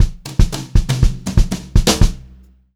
120HRFILL1-R.wav